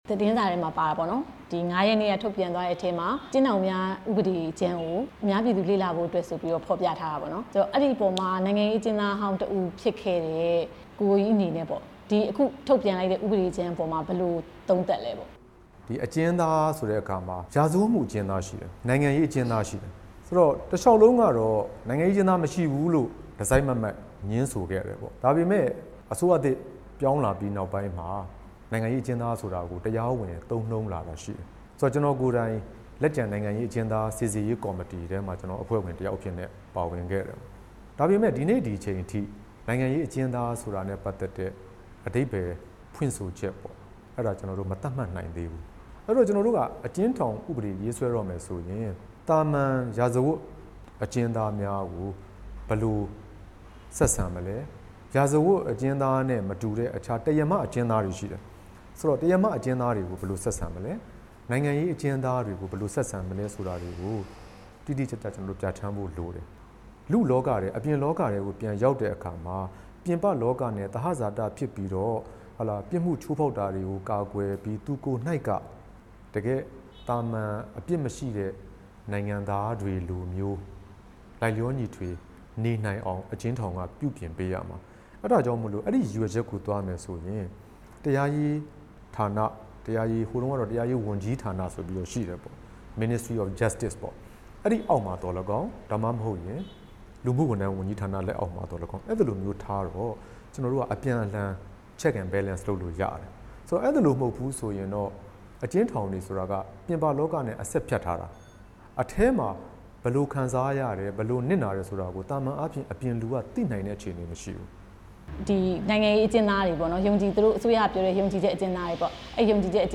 နိုင်ငံရေးအကျဉ်းသားတွေကို ဘယ်လိုဆက်ဆံရမလဲ ဦးကိုကိုကြီးနဲ့ မေးမြန်းချက်